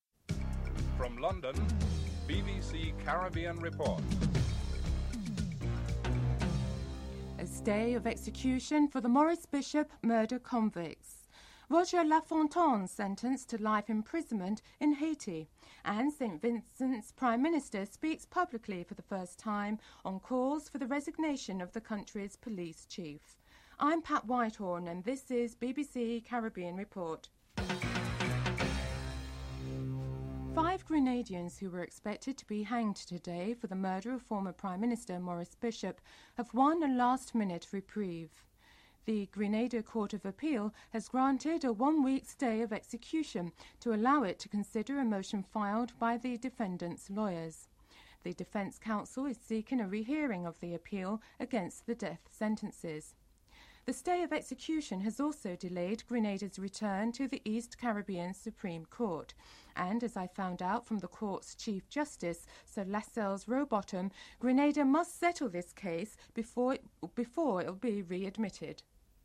Opinions from the man-on-the-street in Grenada are also reported (00:30-07:12)